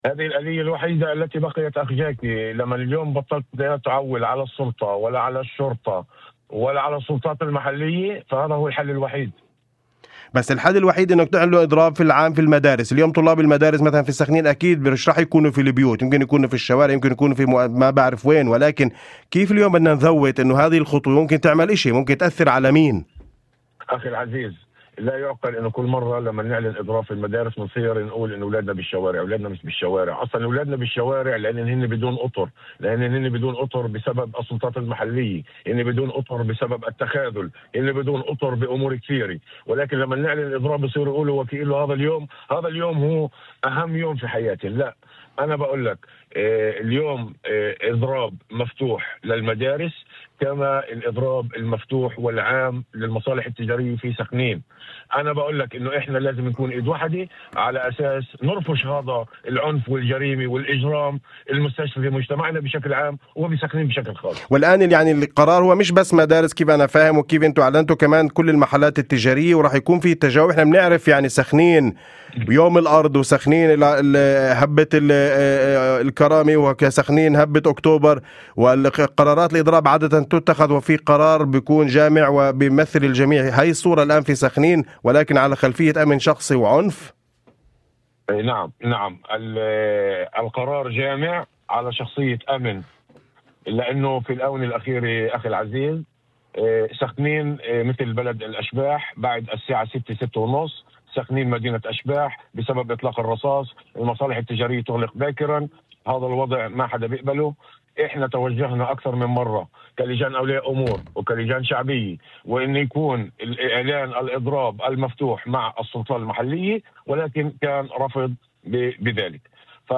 في مداخلة ضمن برنامج "أول خبر" على إذاعة الشمس